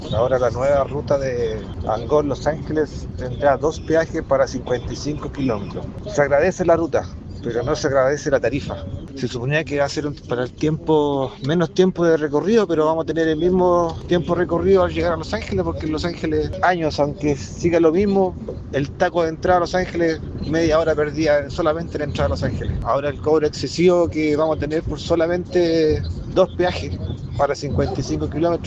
En tanto, un vecino de Angol -quien mantuvo su identidad en reserva-, indicó que viaja comúnmente a Los Ángeles y expresó que encuentra que dos peajes en 55 kilómetros, significa mucho dinero a pagar.